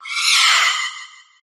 dreepy_ambient.ogg